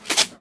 R - Foley 81.wav